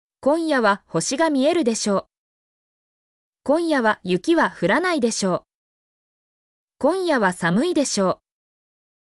mp3-output-ttsfreedotcom-49_ASgO3WiM.mp3